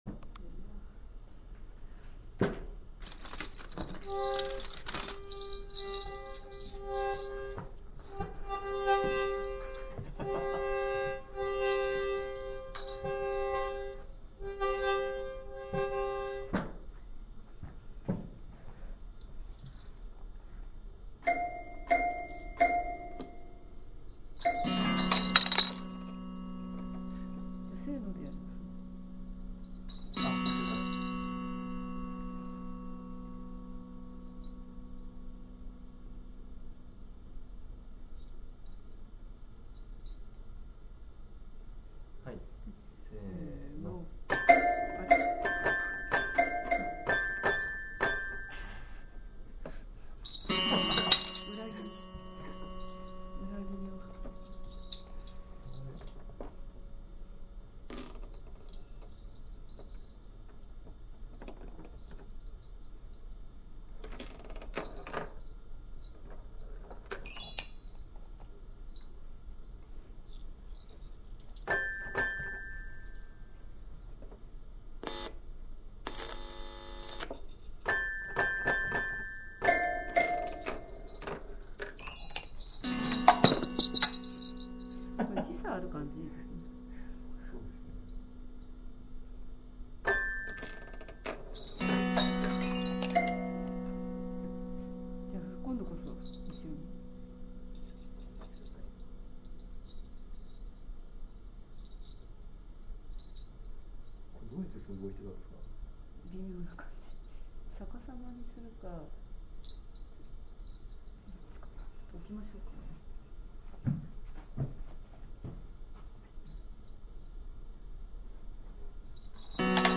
接触すると、ハンガーからノイズをひろってブチブチブチ、と音が鳴るのだ。
ピピッピピーって断続的に鳴ってるのがテスター。
あと音が低くてわかりにくいんだけど、鍵盤叩いたときにブツッ、ブツッ、って言ってるのがオーディオケーブルです。
「なかなかうまく鳴らなくて苦労する3分間」という感じで、これは曲なのか、と言われるとアレなんですけども、こと今回に関しては「音をつかって楽しむ」＝音楽ってことで…。作る過程が楽しかったからそれでいいんじゃないでしょうか！